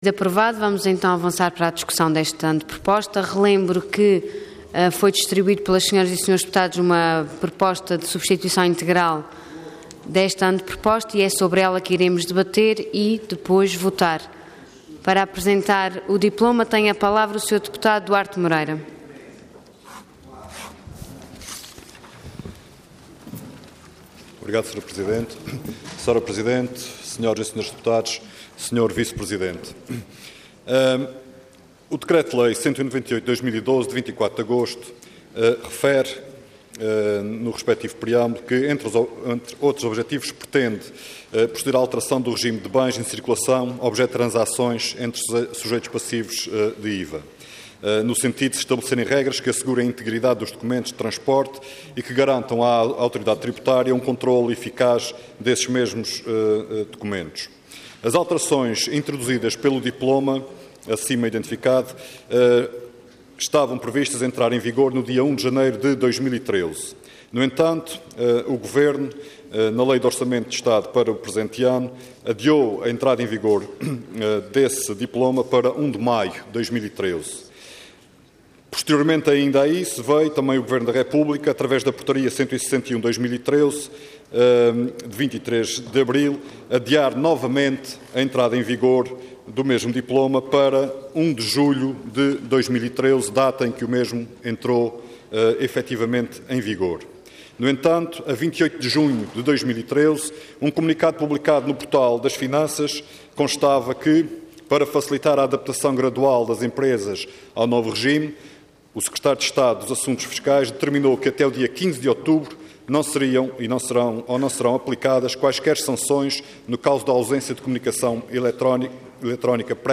Intervenção Anteproposta de Lei Orador Duarte Moreira Cargo Deputado Entidade PS